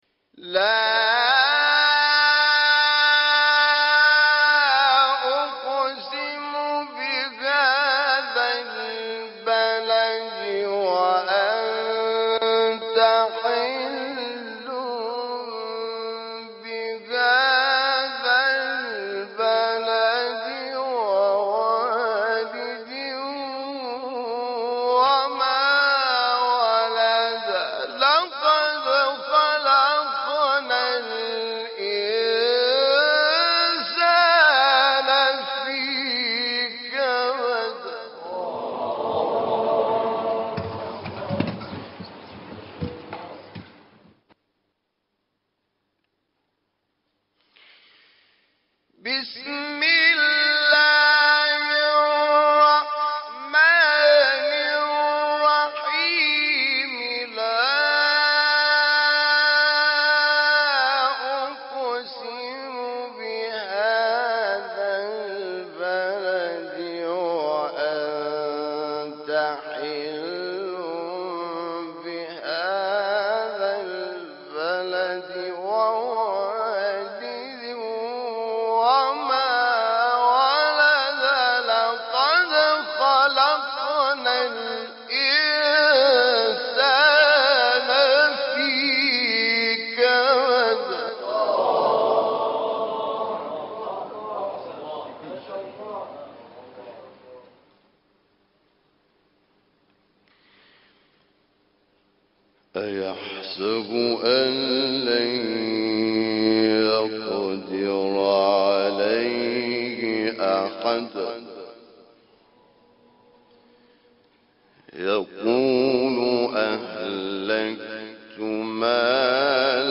سوره : بلد آیه : 1-18 استاد : حامد شاکرنژاد مقام : بیات قبلی بعدی